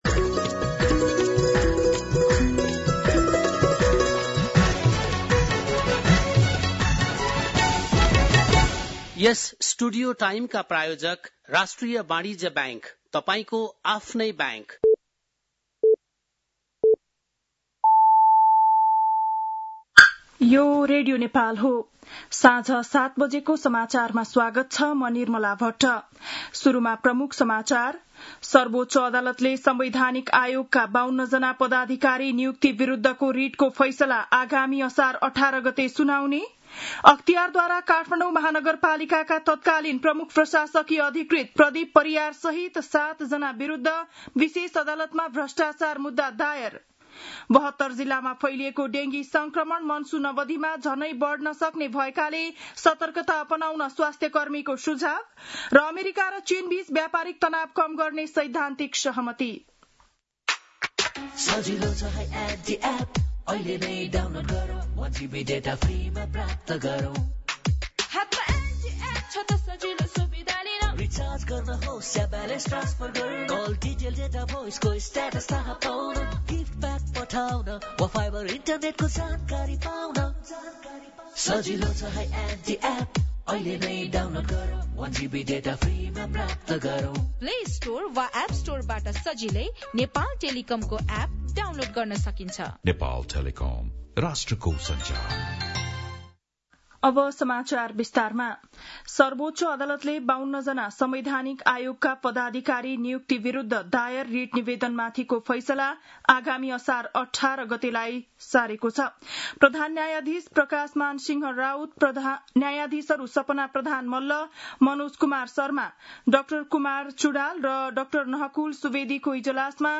बेलुकी ७ बजेको नेपाली समाचार : २८ जेठ , २०८२
7-PM-Nepali-NEWS-02-28.mp3